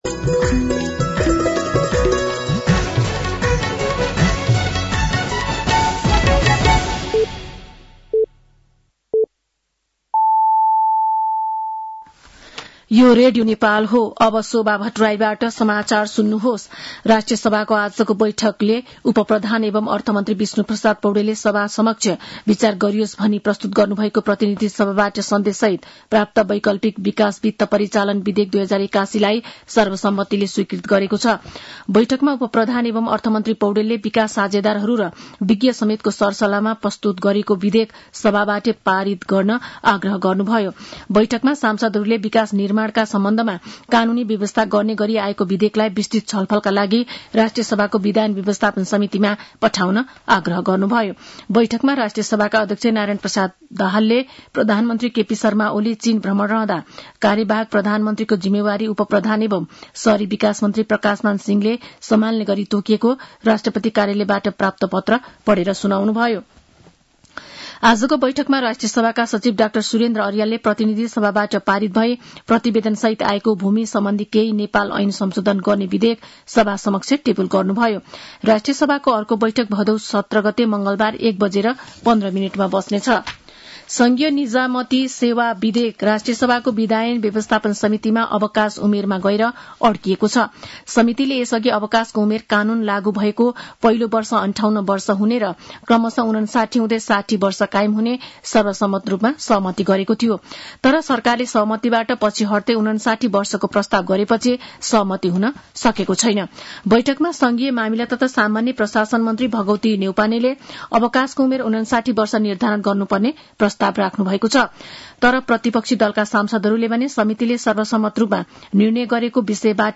साँझ ५ बजेको नेपाली समाचार : १६ भदौ , २०८२
5.-pm-nepali-news-.mp3